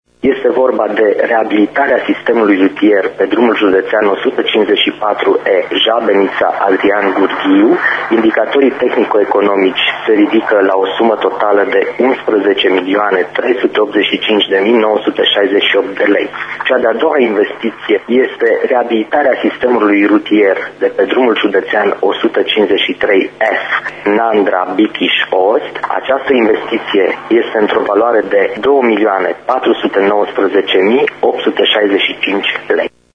Secretarul CJ Mureș, Paul Cosma: